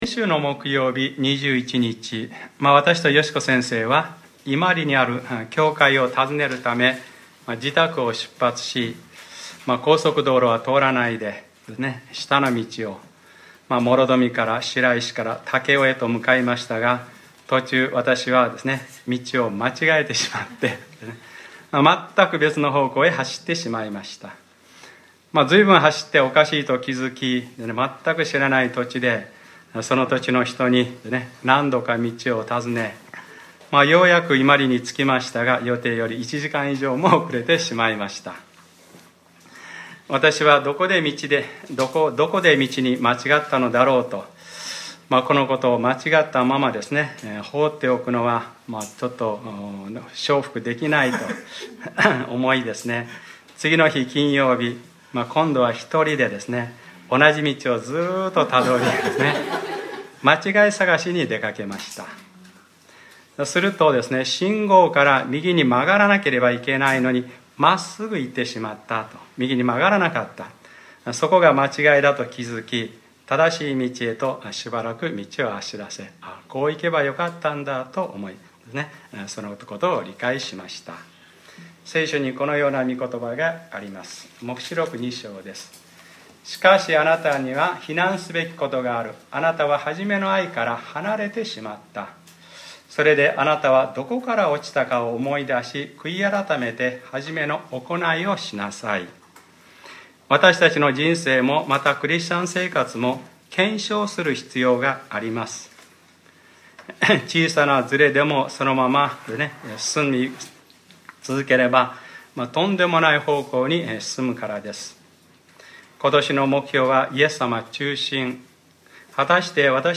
2014年8月24日（日）礼拝説教 『ルカｰ４３：あなたがたの光を人々の前で輝かせ』